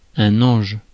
Ääntäminen
Ääntäminen France: IPA: [ɑ̃ʒ] Haettu sana löytyi näillä lähdekielillä: ranska Käännös Konteksti Ääninäyte Substantiivit 1. angel uskonto US UK 2. sweetie US 3. sweetheart US Suku: m .